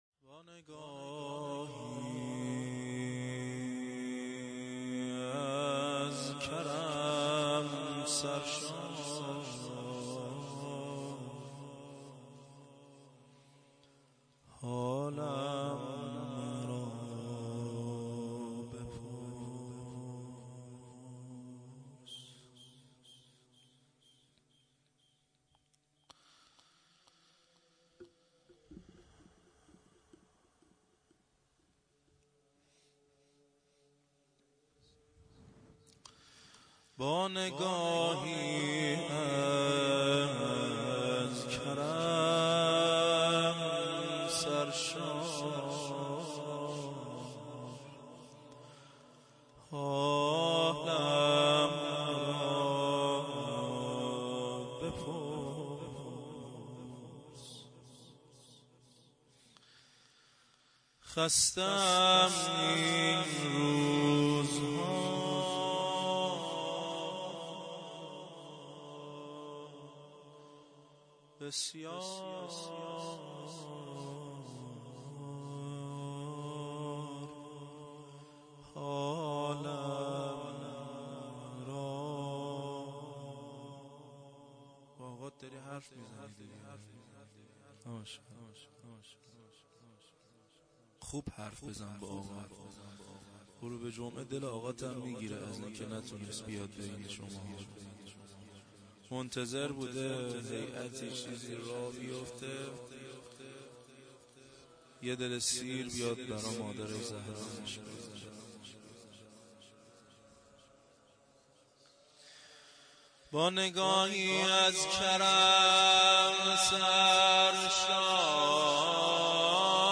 خیمه گاه - هیئت زواراباالمهدی(ع) بابلسر